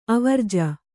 ♪ avarja